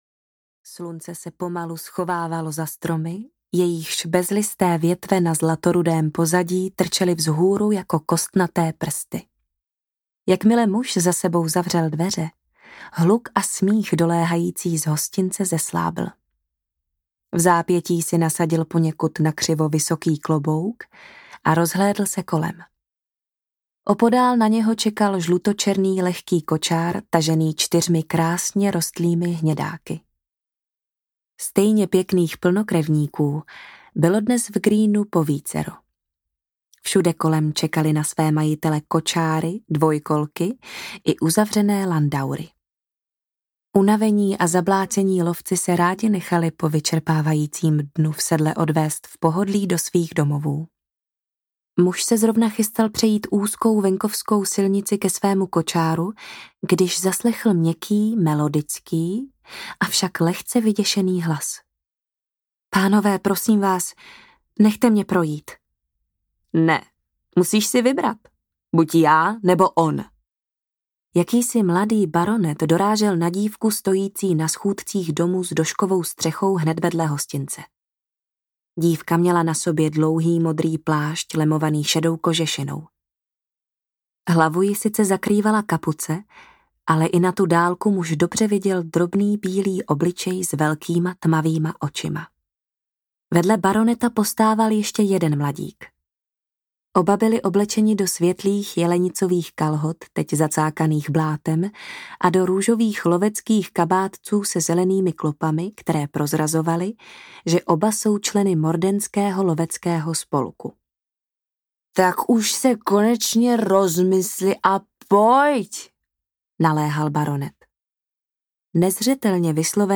Zhýralý markýz audiokniha
Ukázka z knihy